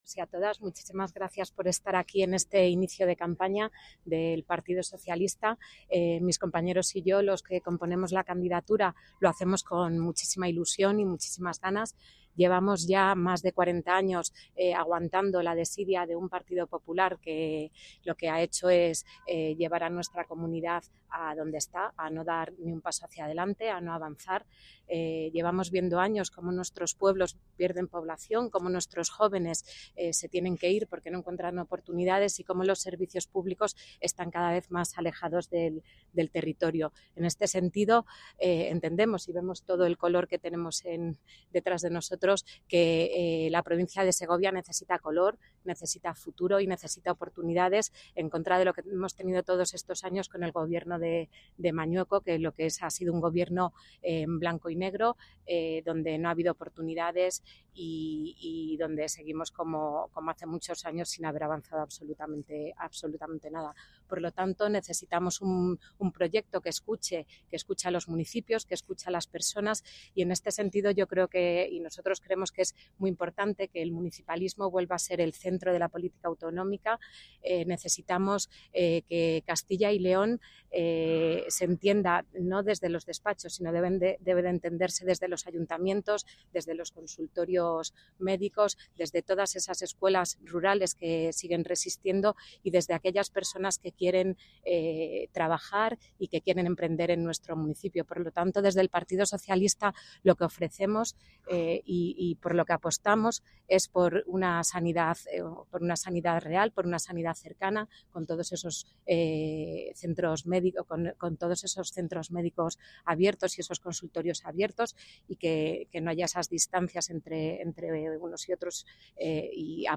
• El PSOE de Segovia ha celebrado su acto de inicio de campaña en el parque del reloj en Nueva Segovia para las elecciones autonómicas del 15 de marzo, reuniendo a la candidatura, militantes y simpatizantes en un encuentro marcado por la ilusión y la convicción de que la provincia necesita un cambio.